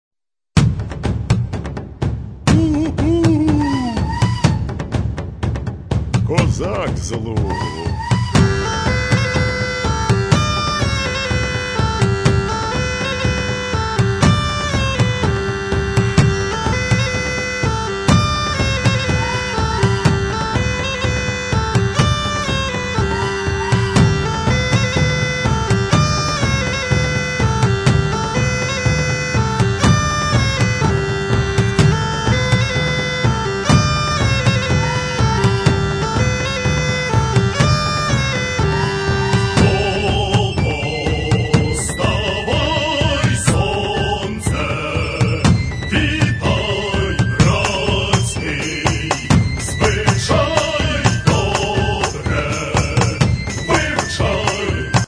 Bandura and Kobza (22)